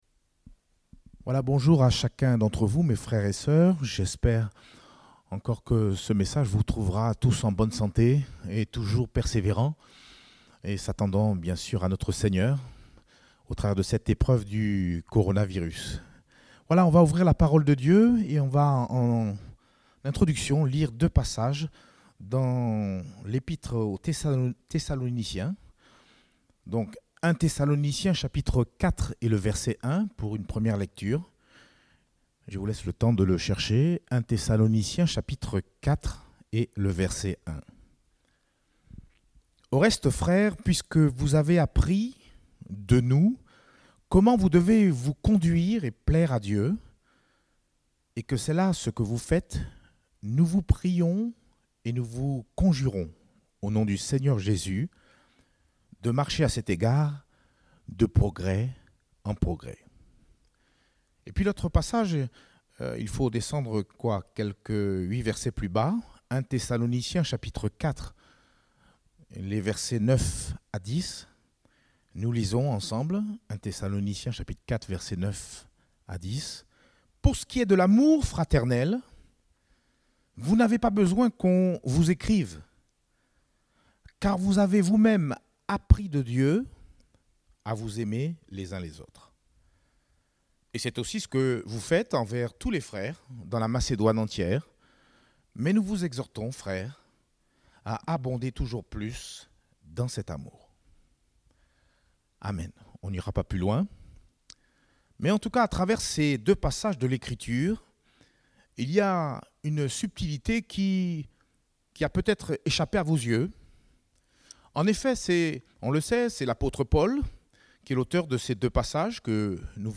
Date : 3 mai 2020 (Culte Dominical)